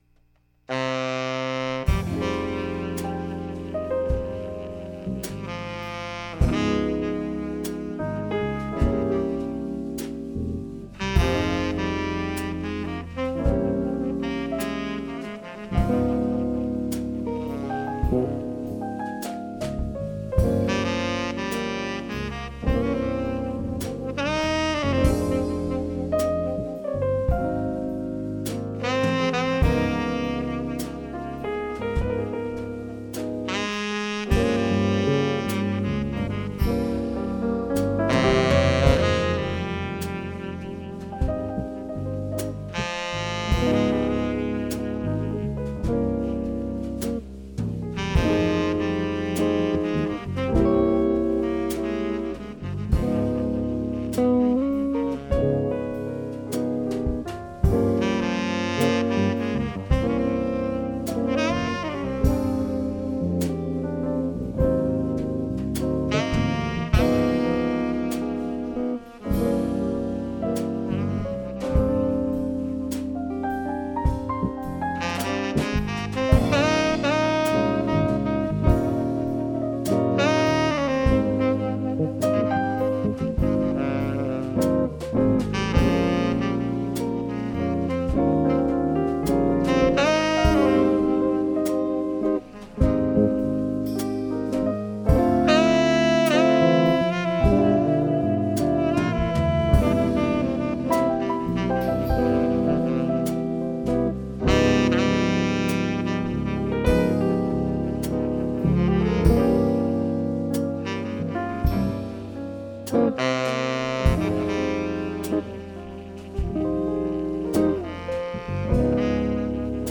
recorded at City College, San Francisco
Sax
Guitar
Keyboards
Bass
Drums